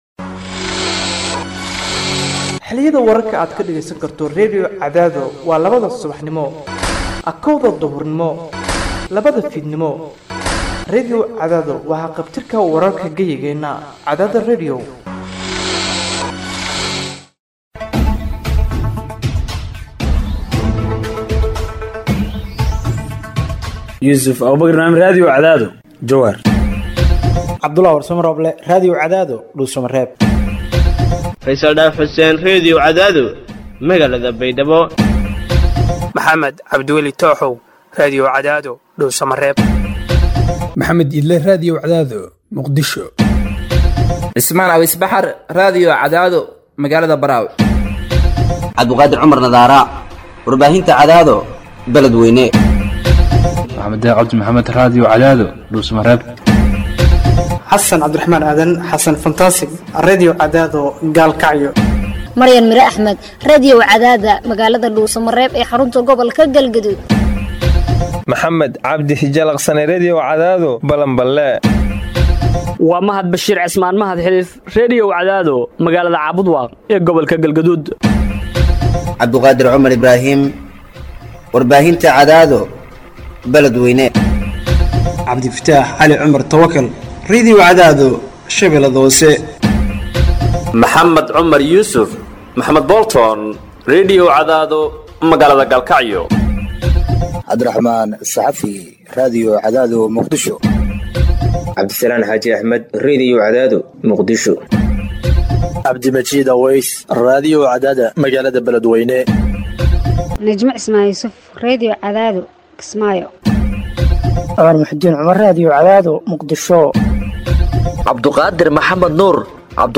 Hoos Ka Dhageyso Warka Habeen Ee Radio Cadaado